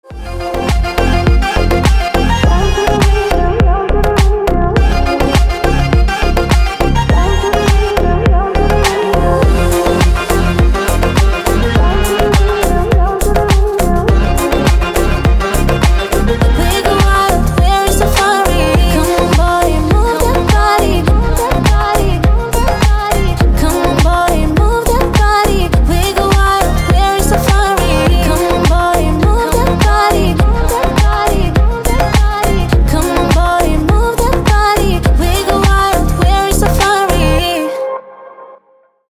• Качество: 320, Stereo
женский вокал
deep house
восточные мотивы
dance
Electronic
EDM
спокойные